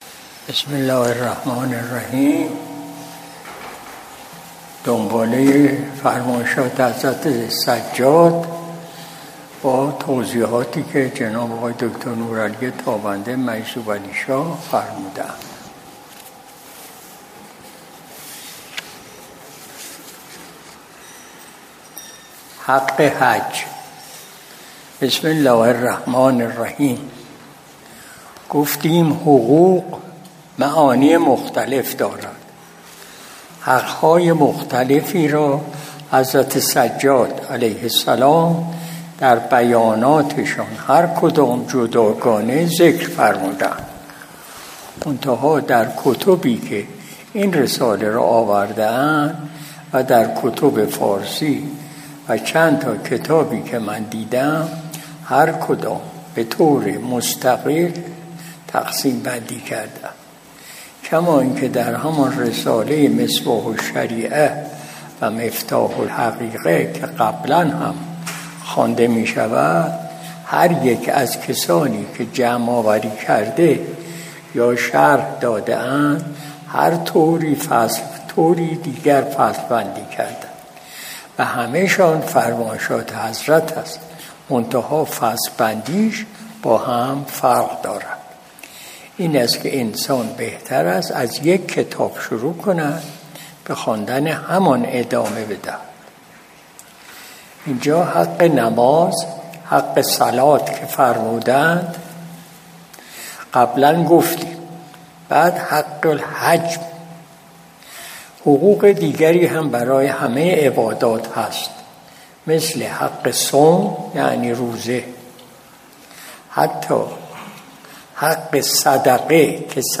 مجلس شب جمعه ۲۲ تیر ماه ۱۴۰۲ شمسی